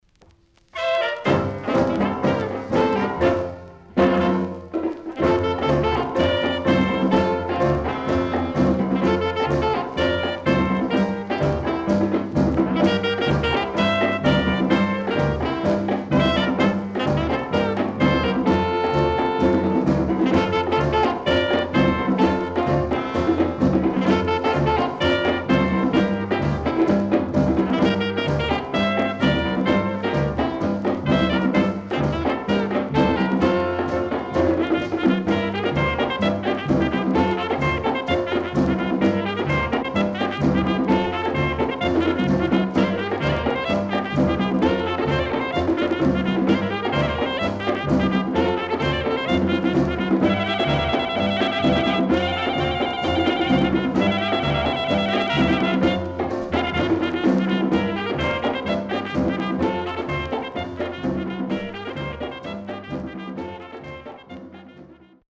BOLERO, MAMBO,CAYPSO等の良曲多数収録。
SIDE A:所々チリノイズ入ります。